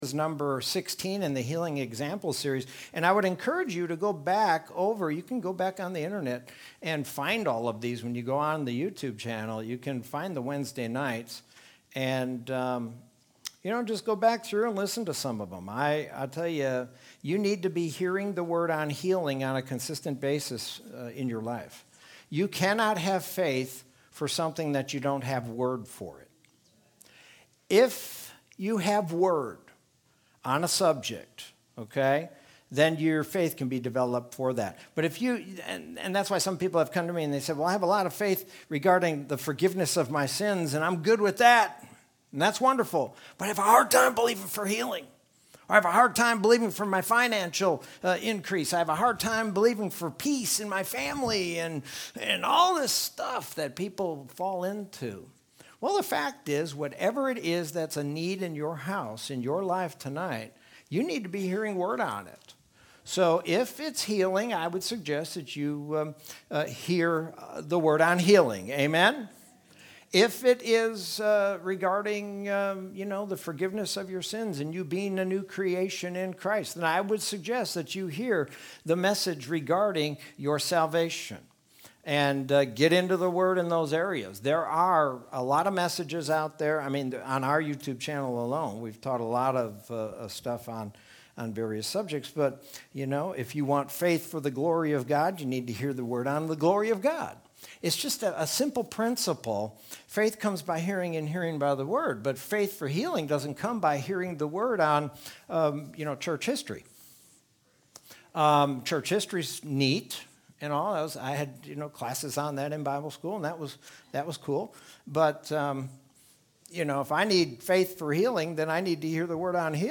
Sermon from Wednesday, May 19th, 2021.